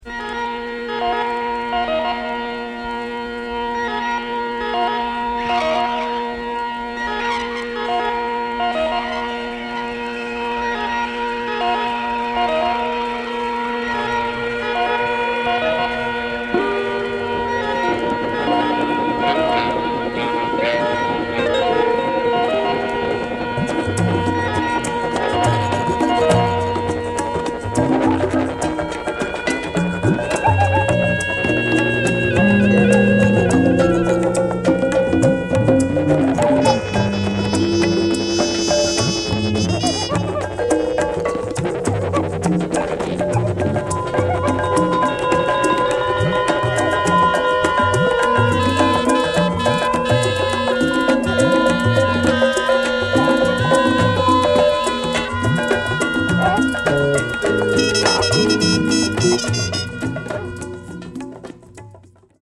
cosmic jazz funk
esoteric Kosmiche Musik